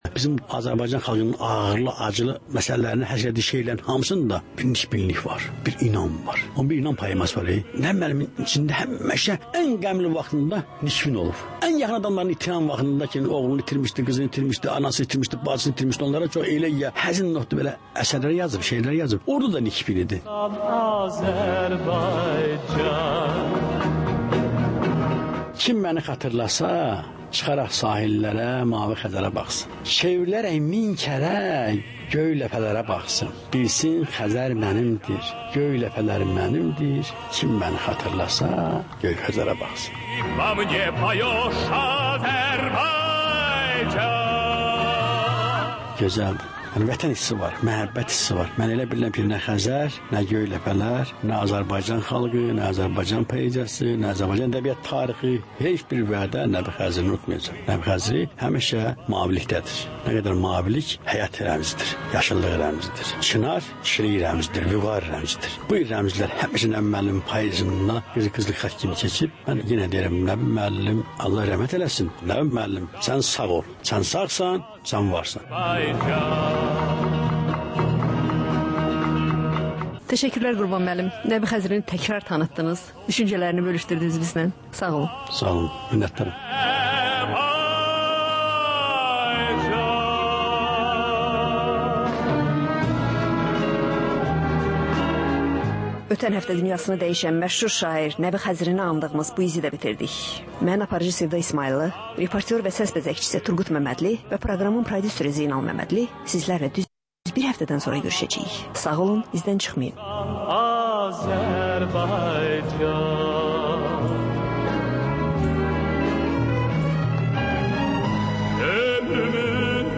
Ölkənin tanınmış simalarıyla söhbət.